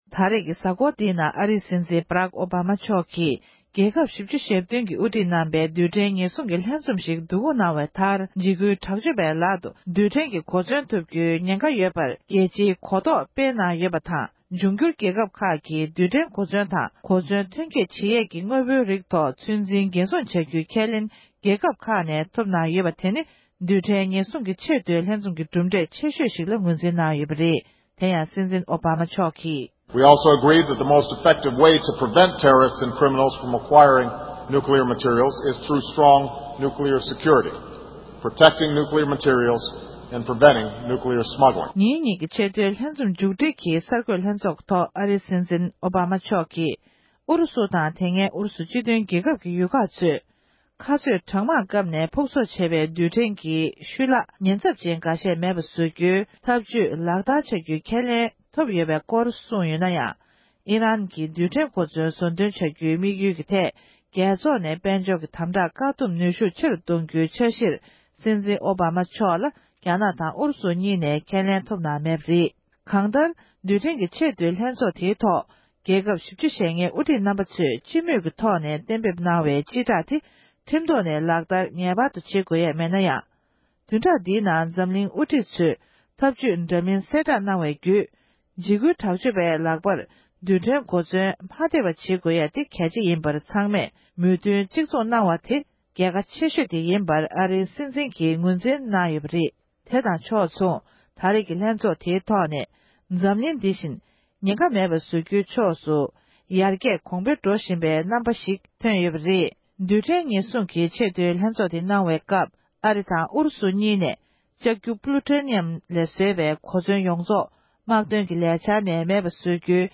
དཔྱད་གཏམ།